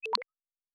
pgs/Assets/Audio/Sci-Fi Sounds/Interface/Digital Click 12.wav at master
Digital Click 12.wav